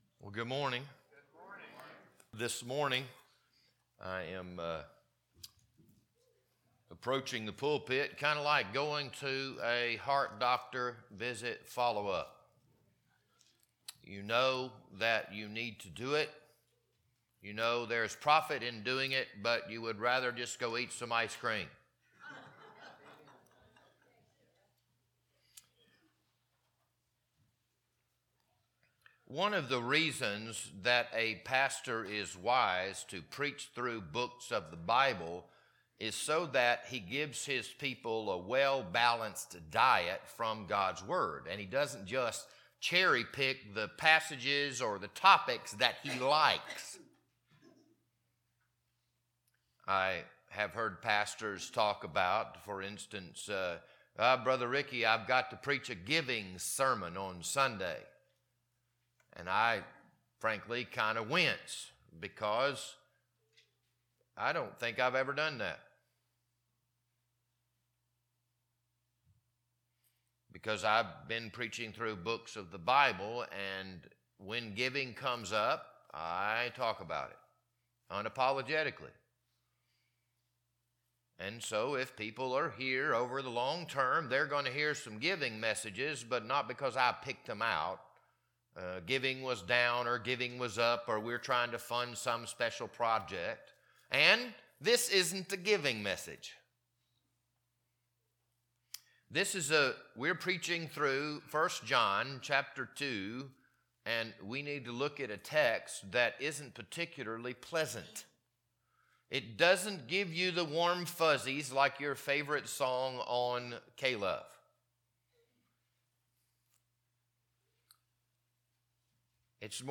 This Sunday morning sermon was recorded on February 8th, 2026.